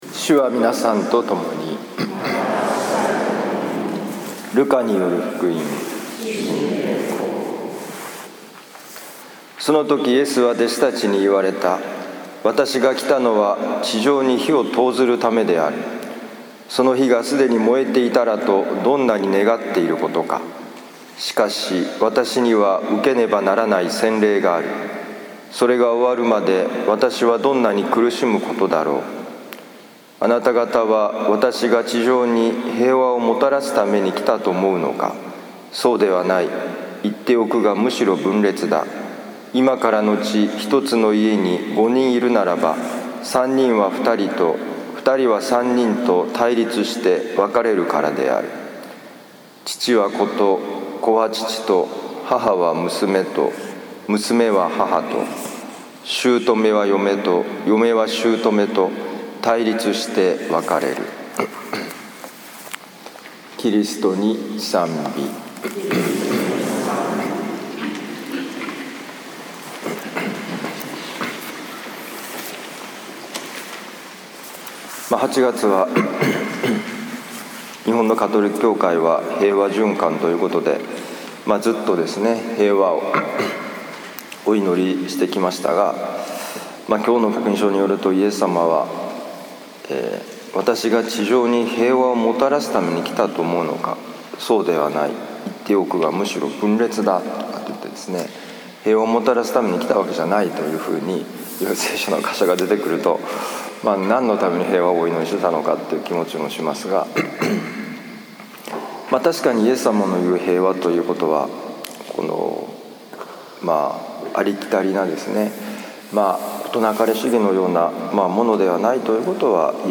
2019年8月18日 聖イグナチオ教会ミサ